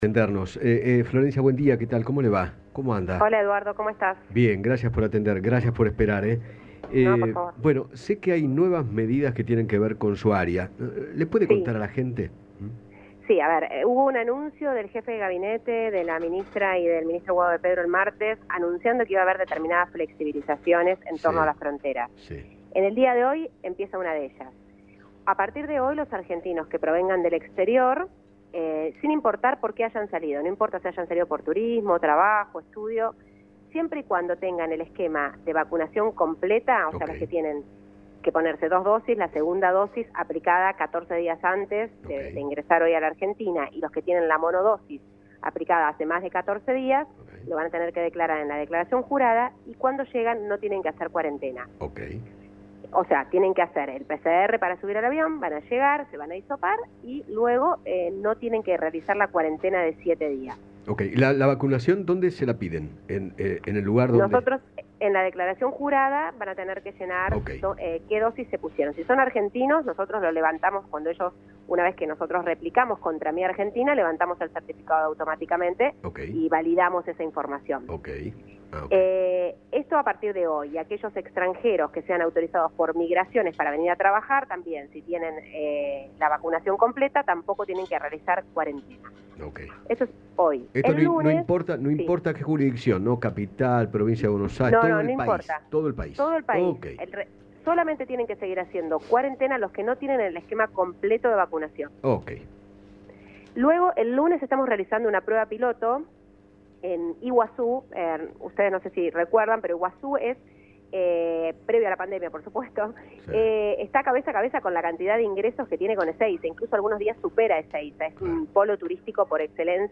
Florencia Carignano, directora nacional de Migraciones, habló con Eduardo Feinmann acerca de la nuevas medidas de flexibilización para los argentinos que ingresen al país. Además, se refirió a la situación de los varados.